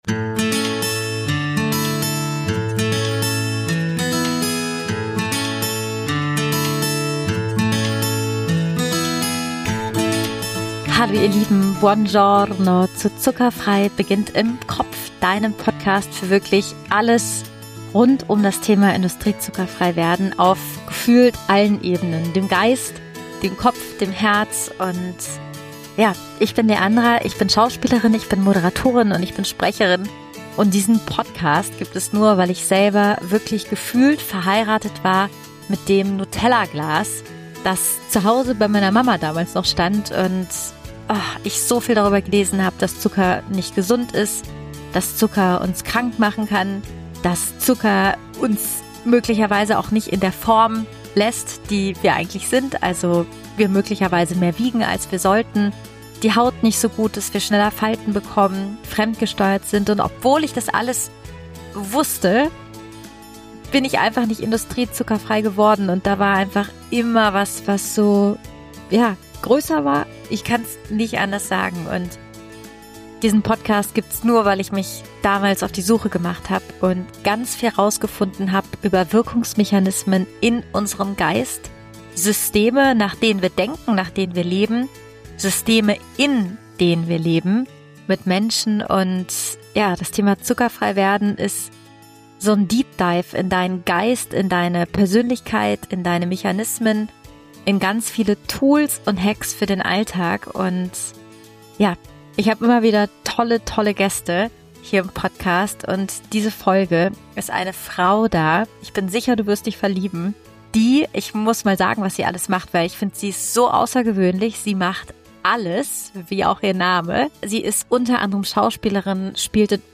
Kennst du schon dein zuckerfreies Lebenselixier?- Interview